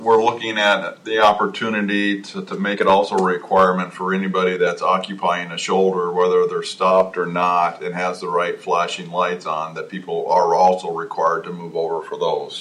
No one died, but State Transportation Secretary Joel Jundt says more needs to be done to protect emergency responders.